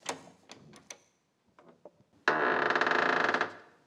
SFX_Door_Open_01.wav